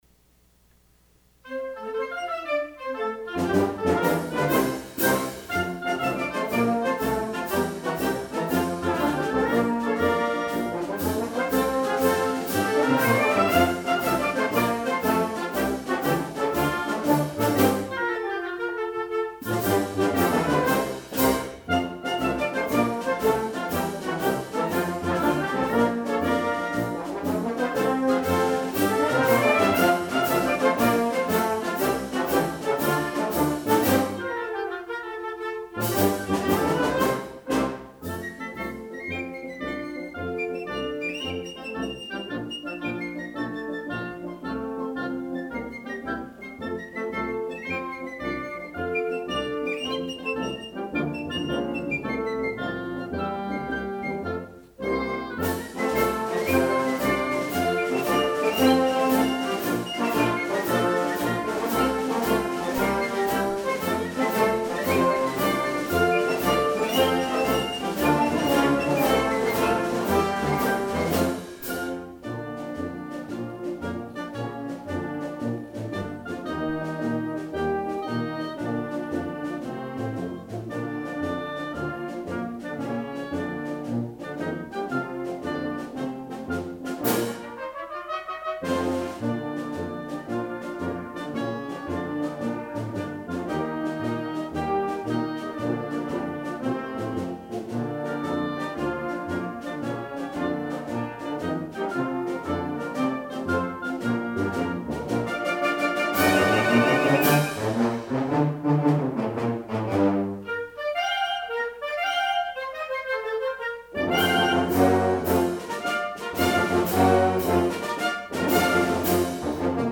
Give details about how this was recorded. These were sight read and recorded on August 13'th 2008.